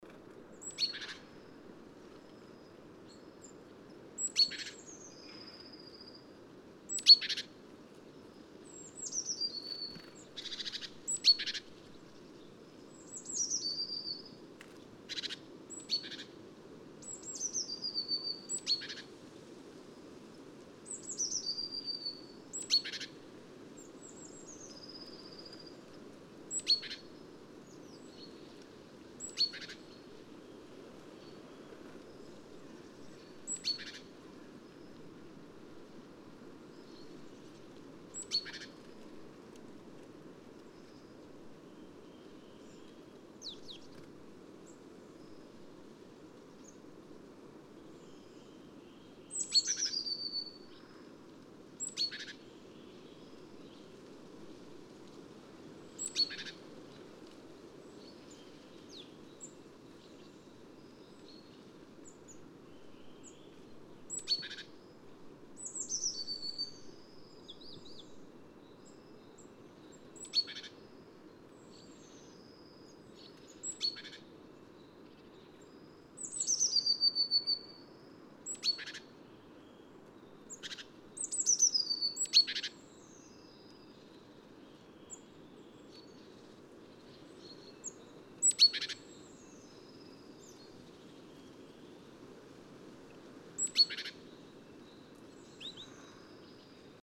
PFR07531, 130203, Marsh Tit Poecile palustris, 2, excitement call, calls